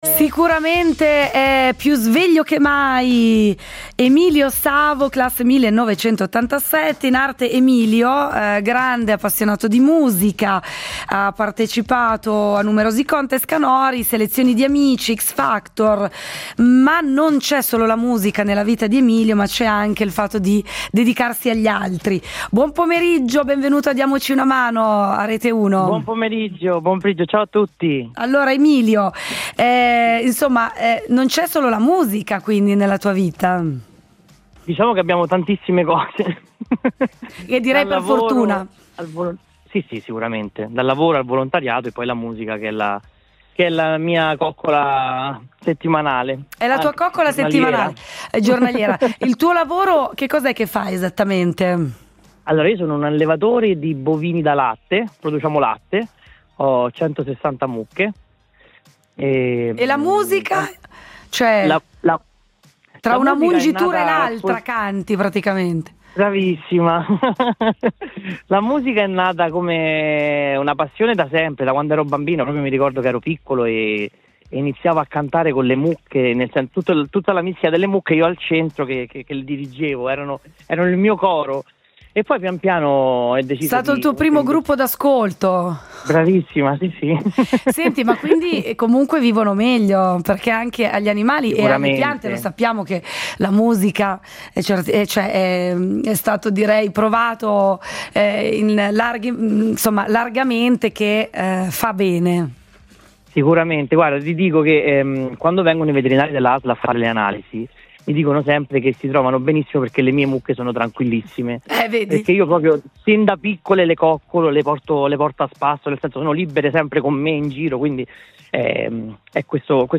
breve intervista